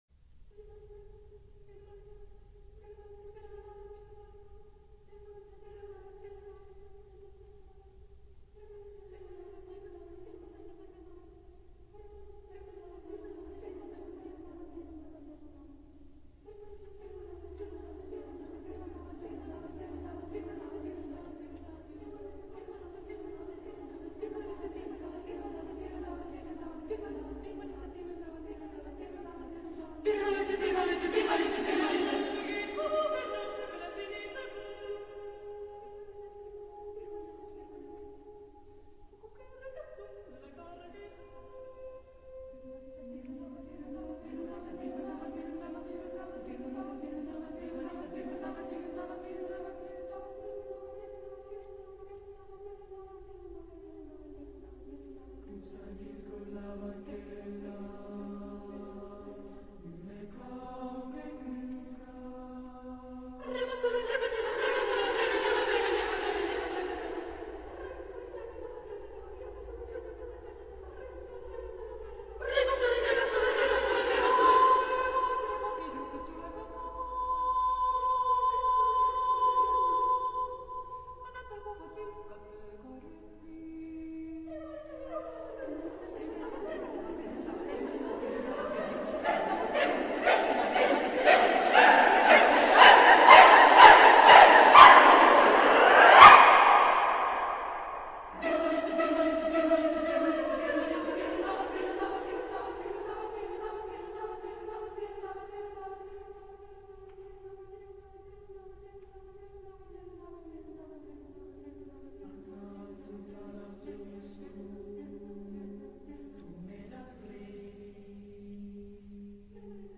Genre-Style-Forme : Profane